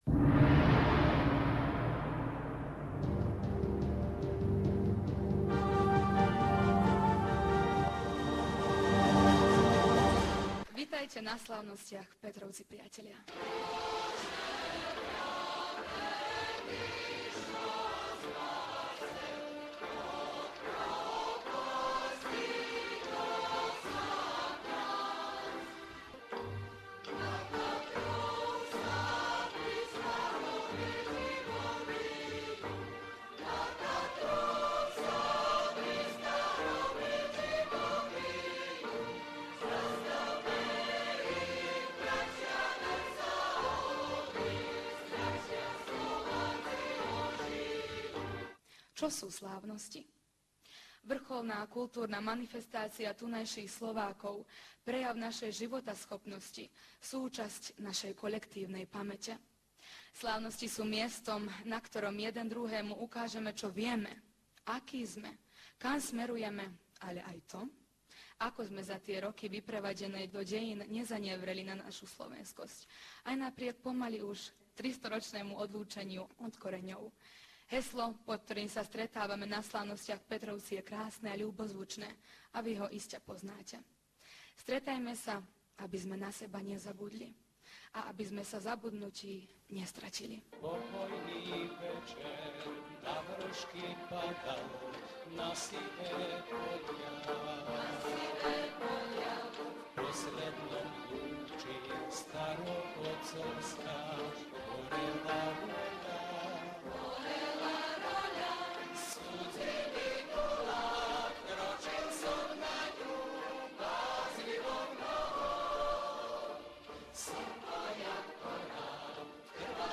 z tohtoročných Slovenských národných slávností v Báčskom Petrovci.